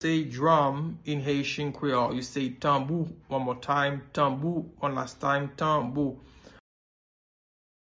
Listen to and watch “Tanbou” audio pronunciation in Haitian Creole by a native Haitian  in the video below:
9.How-to-say-Drum-in-Haitian-Creole-–-Tanbou-pronunciation.mp3